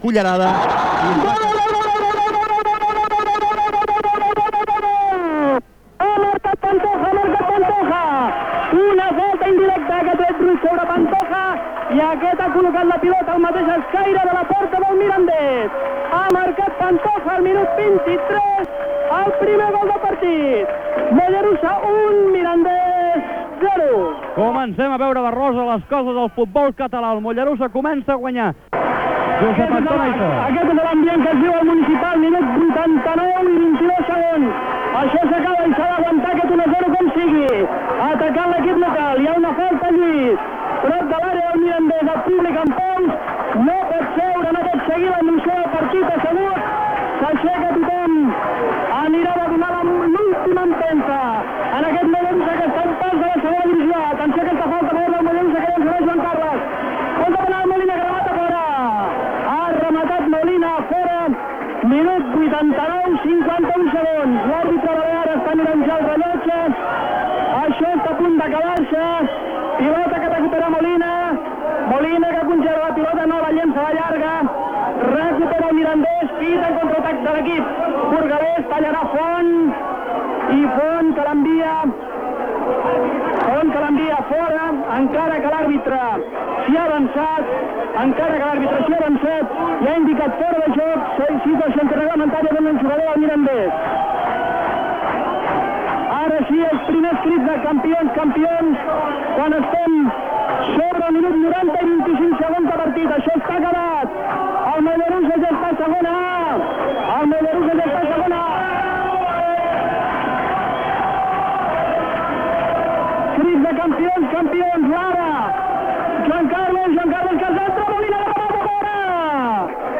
Connexió amb el camp de futbol del Mollerussa que juga contra el Mirandés. Cant del primer gol del Mollerussa. Retransmissió de la part final del partit de futbol masculí. Narració del segon gol i acabament del partit. El Mollerussa puja a Segona Divisió A.
Esportiu